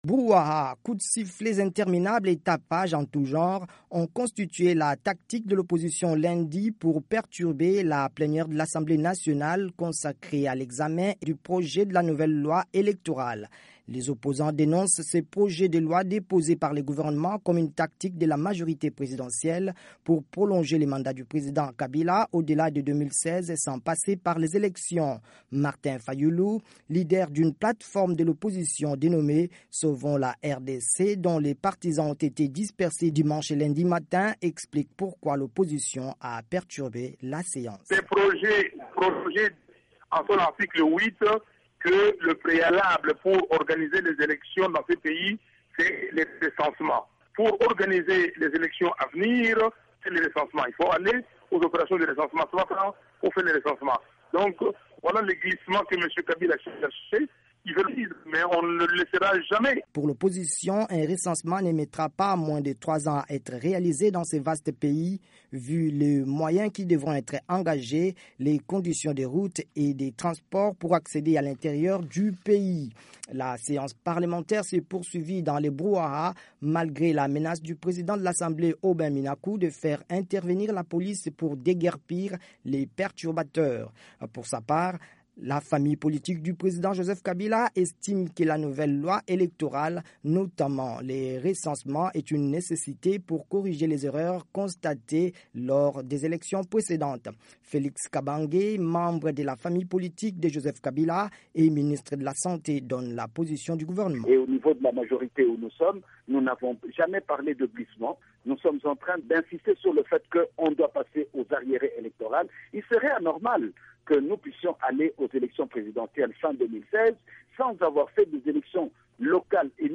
Brouhaha, coup de sifflets interminables et tapages en tout genre ont constitué la tactique de l’opposition lundi pour perturber la plénière de l'Assemblée nationale consacrée à l'examen de la nouvelle loi électorale.